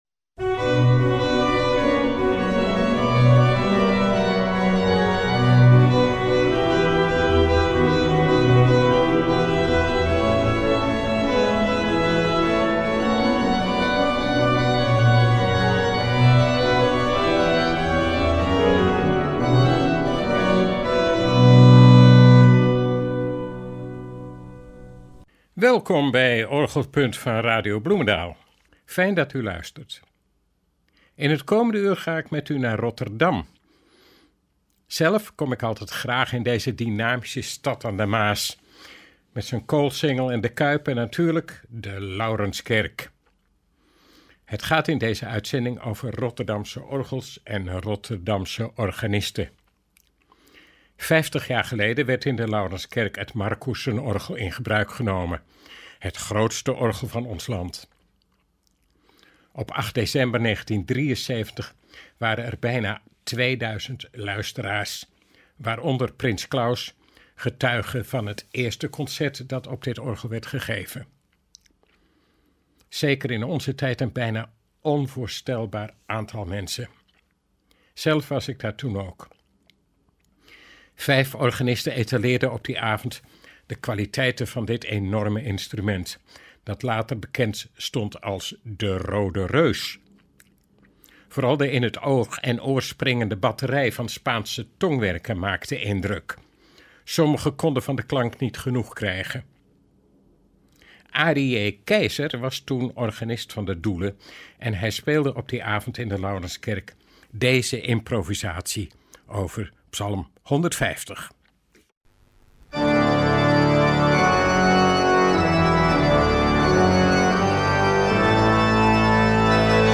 enkele nieuwe en oude opnamen van het Marcussen-orgel laten horen.